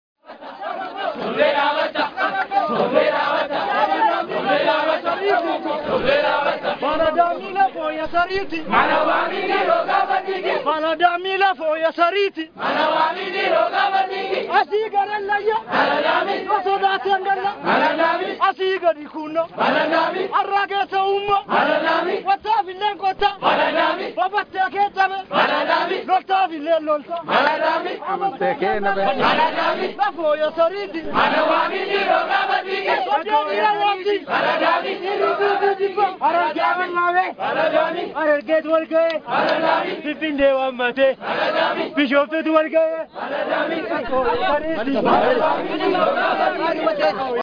“Taaddee Birruu” Wallee Qeerroo Irreecha 2015